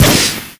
tire_break.ogg